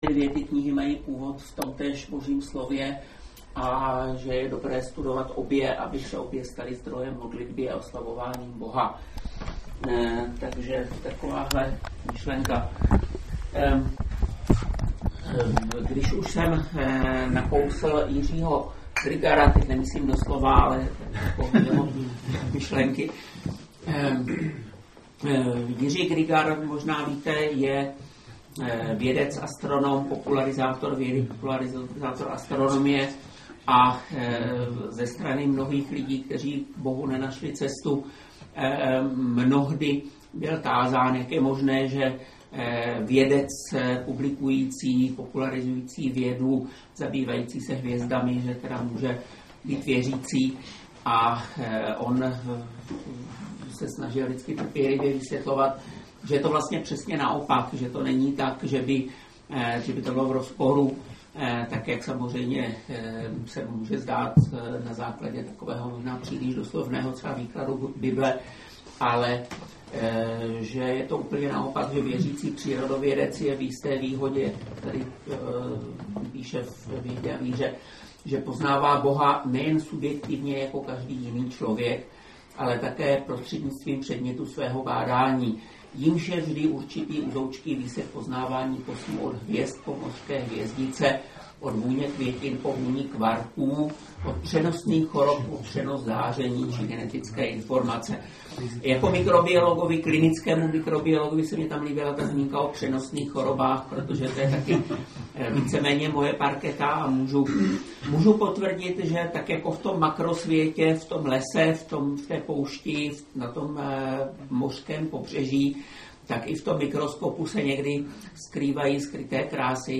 24. dubna jsme ve skautské klubovně v Bystrci prožili oslavu svátku všech skautů.